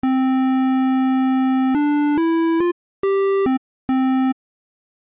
これがオルガンタイプのエンベロープ。
素早く立ち上がり、それ以降音量が安定していて、使いやすい。
●オルガンエンベロープの音色サンプル
organtype.mp3